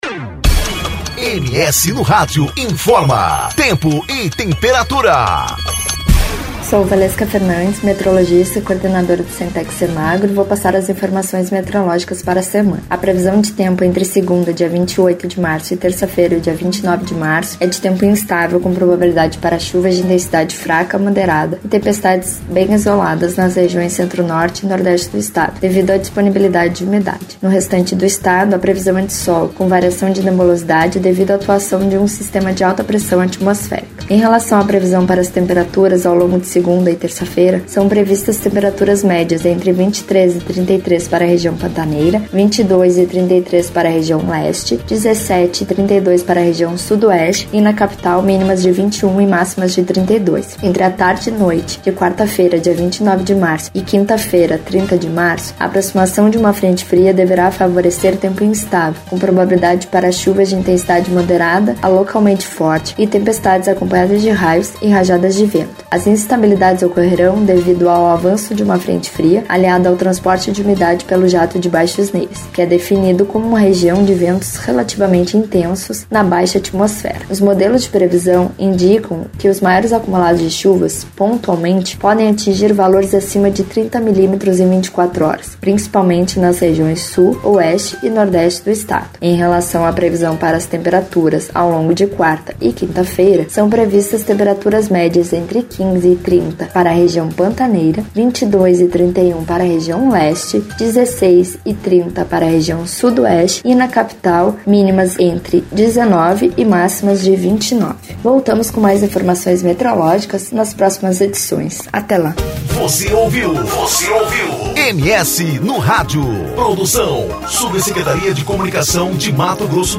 Previsão do Tempo: Semana começa com tempo instável e nova frente fria se aproxima do MS